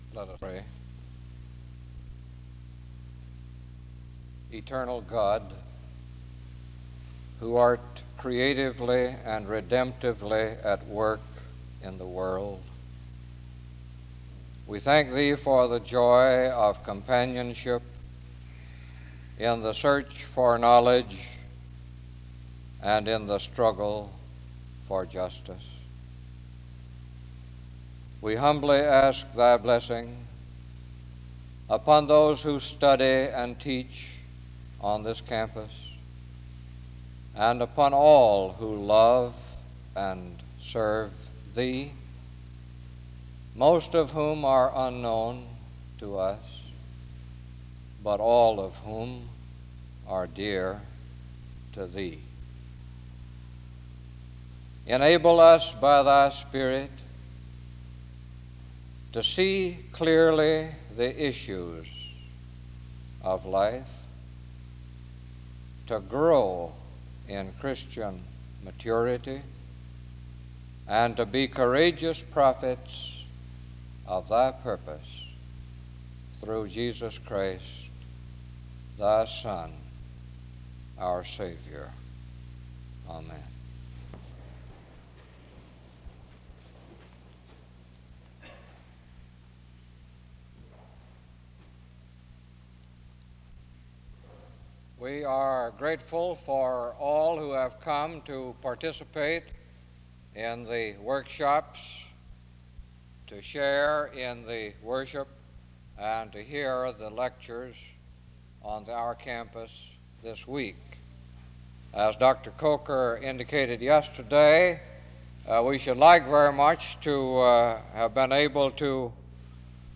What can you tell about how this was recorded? The service begins with a word of prayer (0:00-1:26).